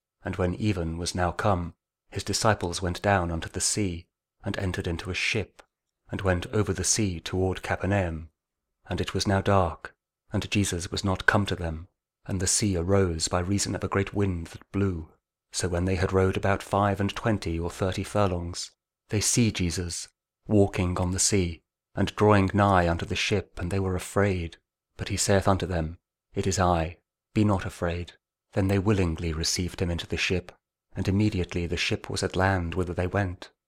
John 6: 16-21 Audio Bible KJV | King James Audio Bible | Daily Verses